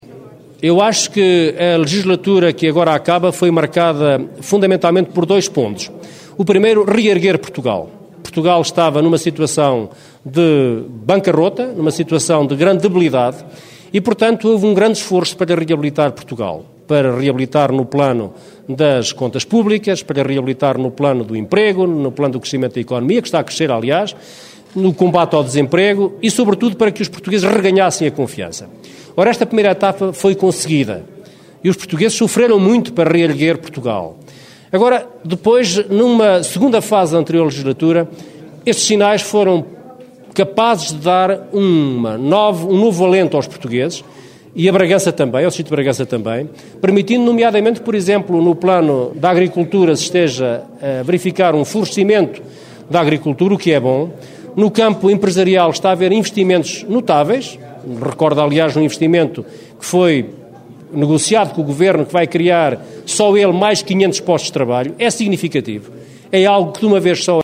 Declarações de Adão Silva, na apresentação oficial da lista pela coligação PSD/CDS-PP pelo distrito de Bragança, que aconteceu em Alfândega na Fé.